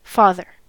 Konsonant (in IPA). Byspilwort Audiobyspil (amerikanischi Ussprooch)
Vokal Byspilwort Audiobyspil Vokal Byspilwort Audiobyspil
En-us-father.ogg